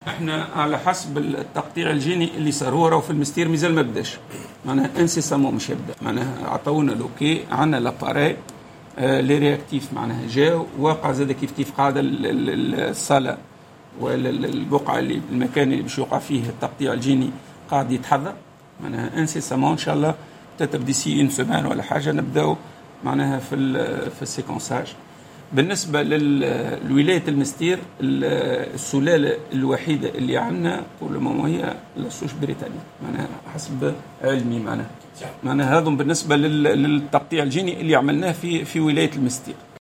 أكد المدير الجهوي للصحة بالمنستير حمودة الببا اليوم الخميس في تصريح أن السلالة الوحيدة التي تم تسجيلها بالجهة الى غاية اليوم هي السلالة البريطانية.